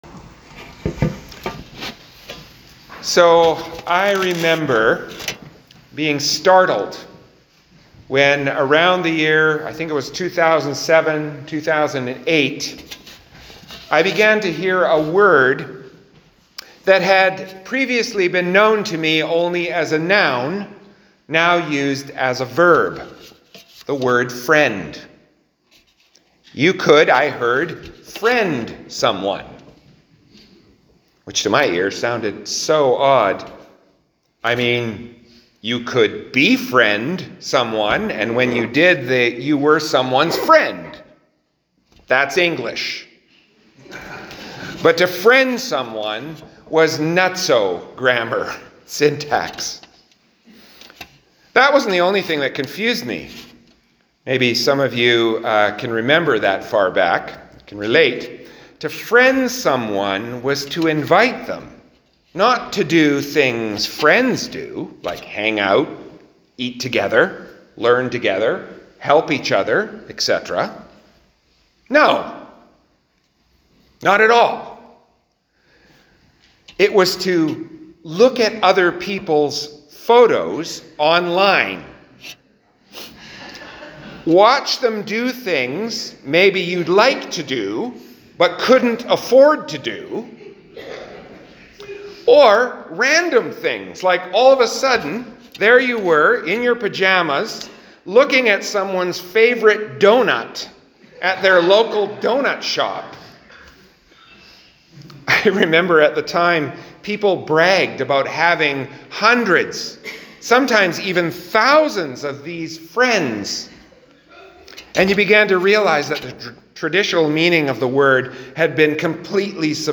Sermon 5 May 2024, Easter 6